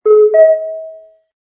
sfx_deskbell01.wav